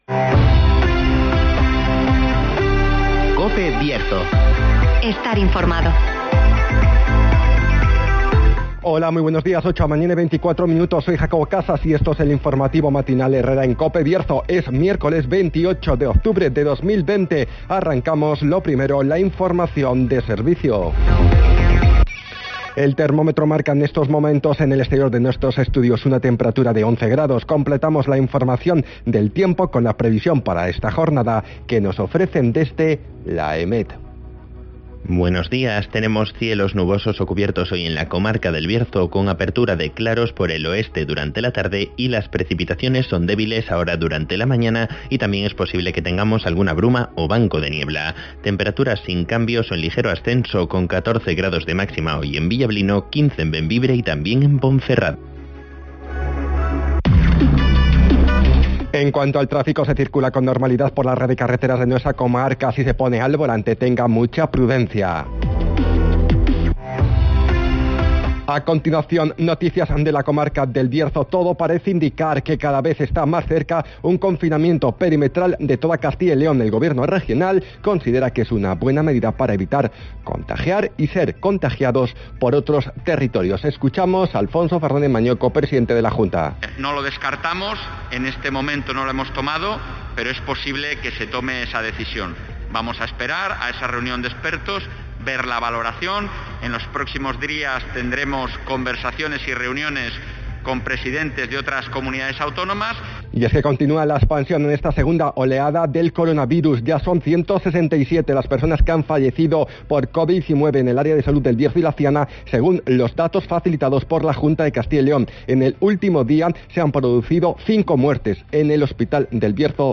INFORMATIVOS
Conocemos las noticias de las últimas horas de nuestra comarca, con las voces de los protagonistas.
-Todo parece indicar que cada vez esta vez está más cerca un confinamiento perimetral de toda Castilla y León (Palabras de Alfonso Fernández Mañueco, presidente de la Junta)